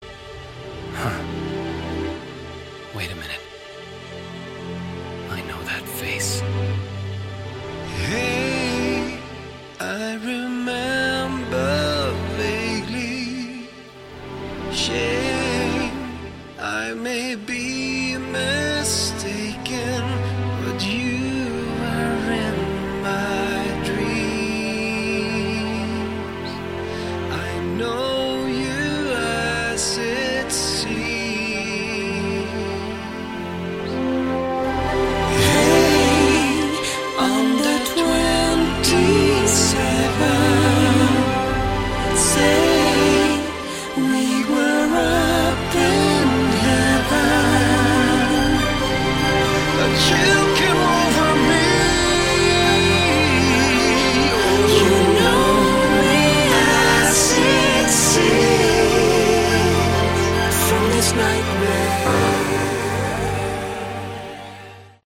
Category: Melodic Synmphonic Prog Metal
lead and background vocals
drums, keyboards
guitars
bass guitar